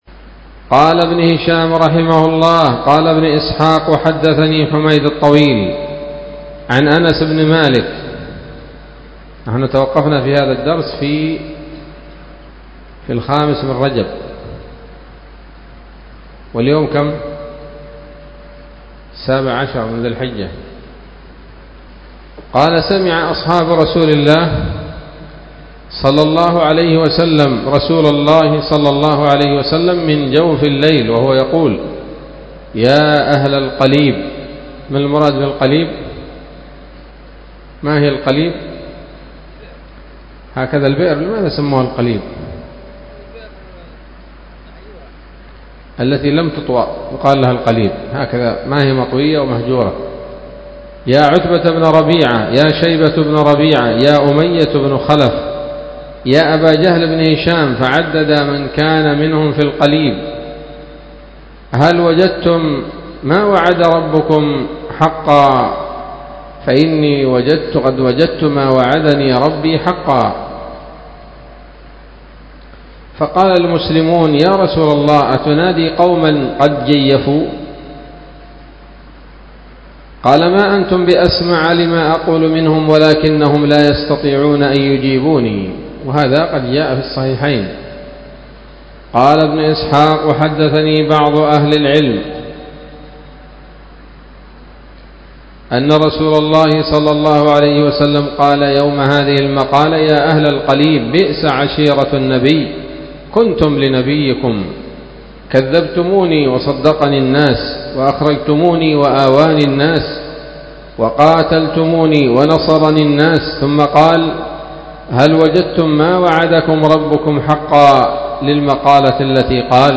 الدرس العشرون بعد المائة من التعليق على كتاب السيرة النبوية لابن هشام